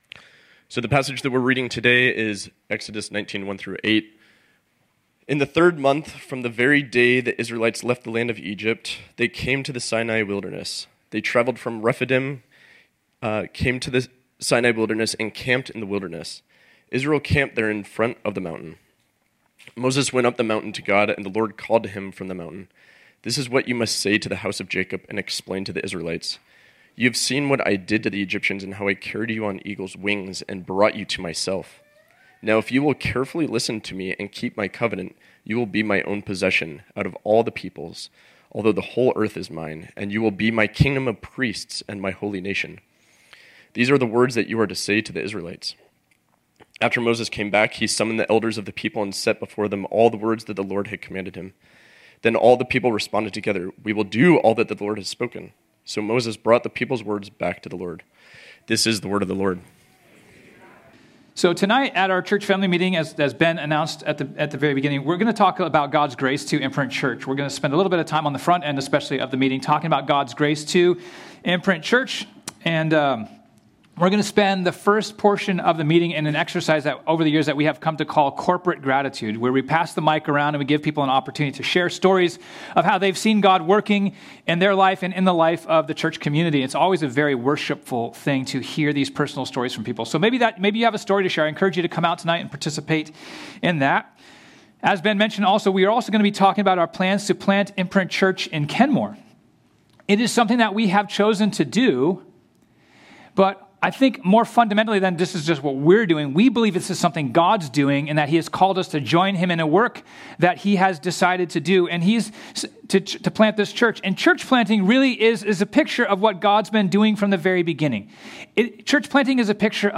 ” our sermon series on the book of Exodus.